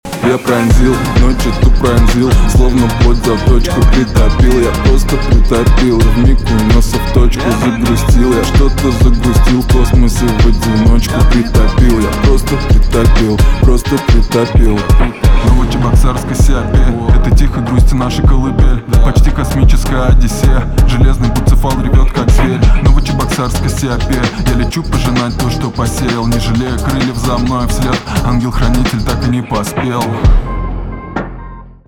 русский рэп
грустные
спокойные